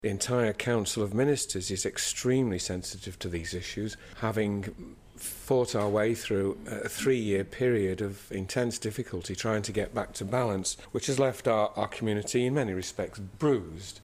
Policy and Reform Minister Chris Robertshaw says the issue is being looked at: